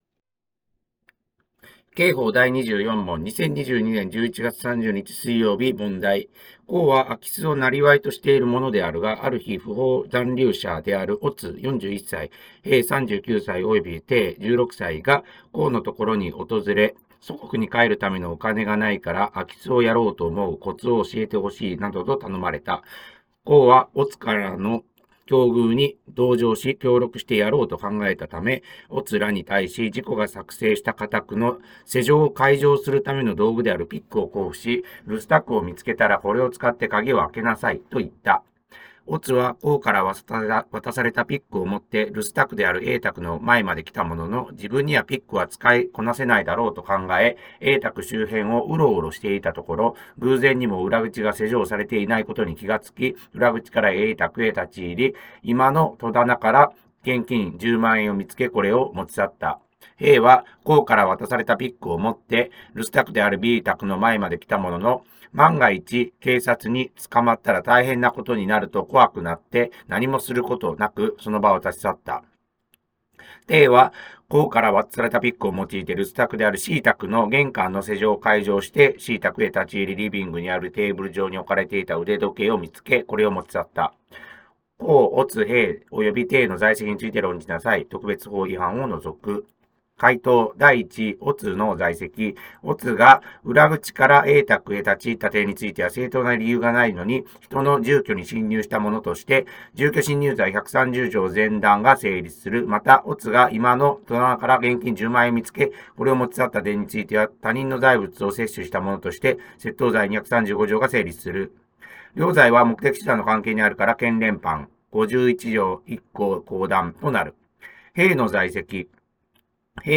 問題解答音読